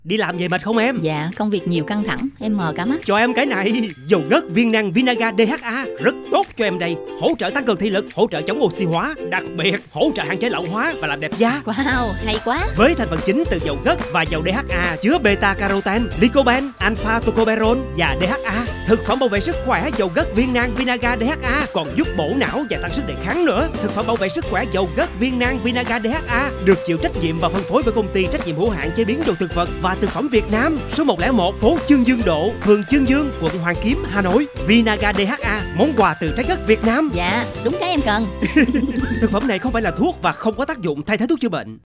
4. Quảng cáo truyền thanh
Nội dung quảng cáo tồn tại dưới dạng âm thanh gồm phần lời, phần nhạc, được lưu trữ nội dung dưới dạng file âm thanh (Mp3…).